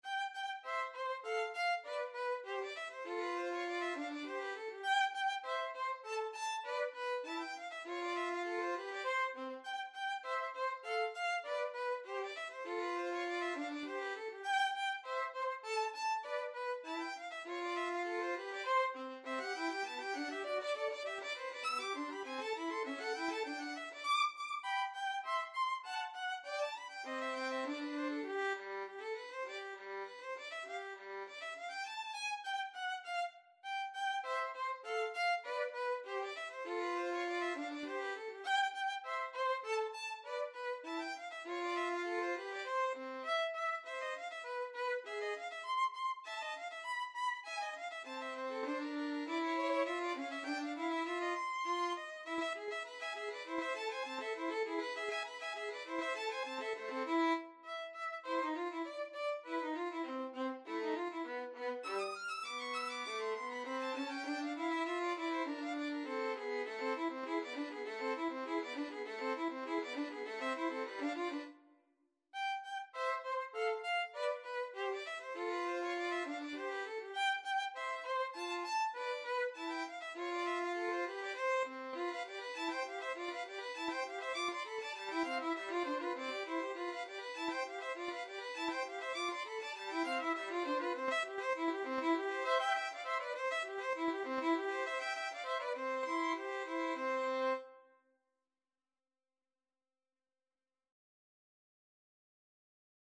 2/4 (View more 2/4 Music)
Violin Duet  (View more Advanced Violin Duet Music)
Classical (View more Classical Violin Duet Music)